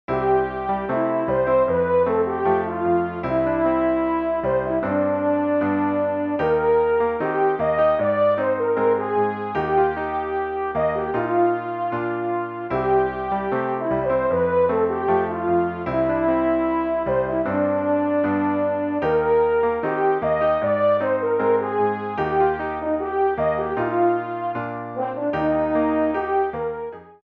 C  F  C  G  |Eb  Ab  Eb  Bb
You’ve now got 16 bars of music that’s oscillated back and forth between C major and Eb major.
Here’s a quick MIDI file (converted to MP3) in a ballad style that demonstrates this principle, using the chord progression above.